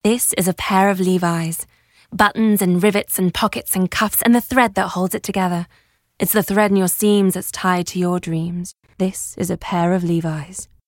Commercial V/O Levis - Standard British Accent
Warm, Inspiring, Optimistic